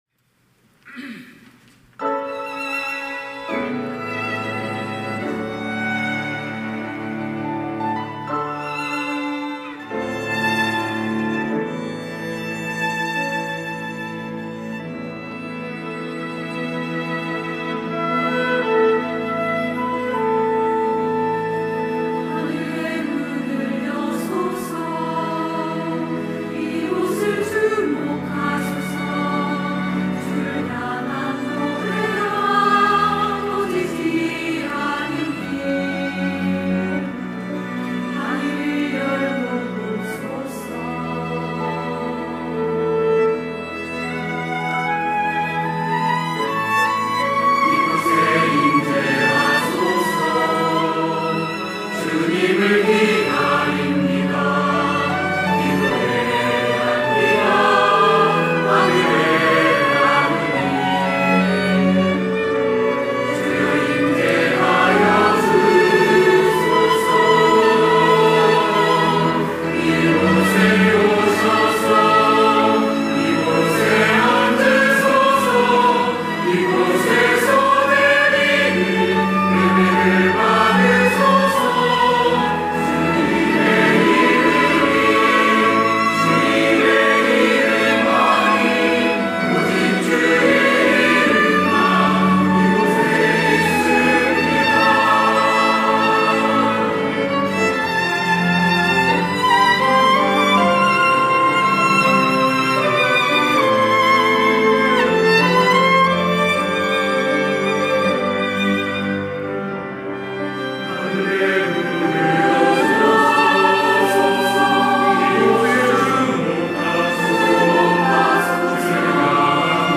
찬양대 호산나